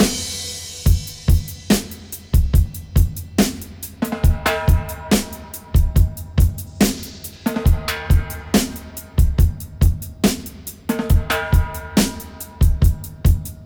141-FX-04.wav